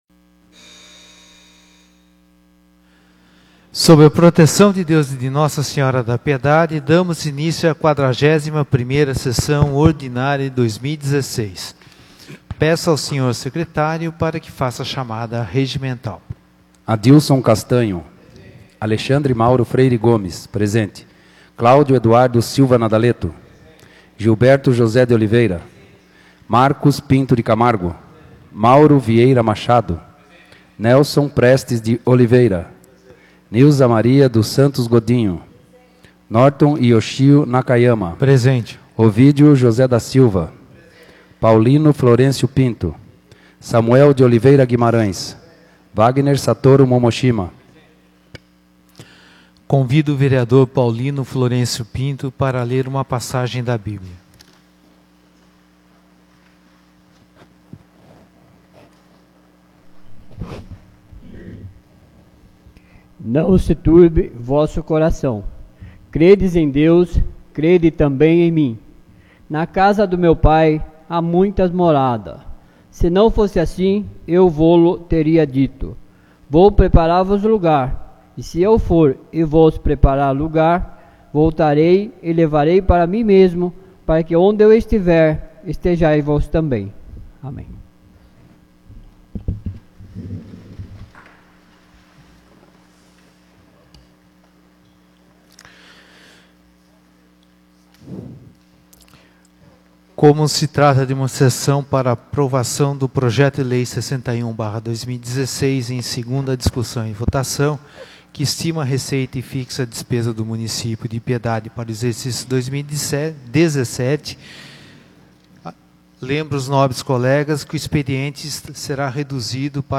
41ª Sessão Ordinária de 2016